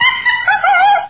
Звуки лемура
Звуки лемуров в дикой природе